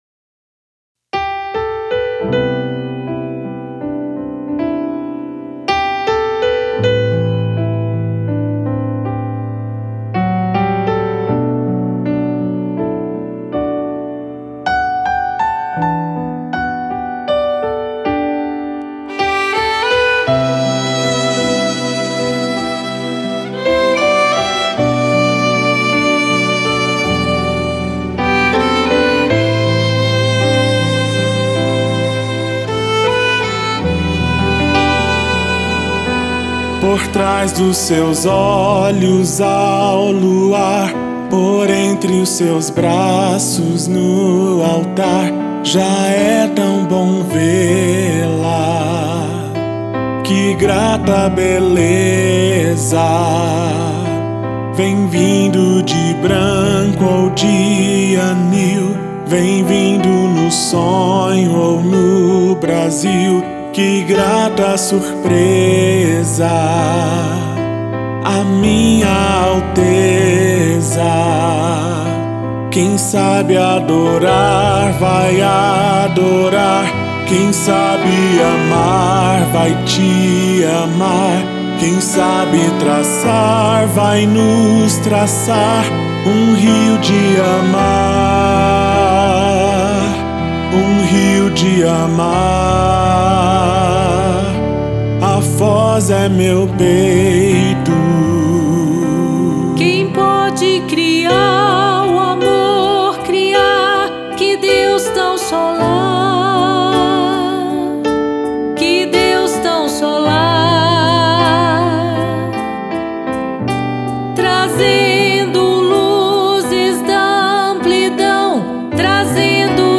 • Violinista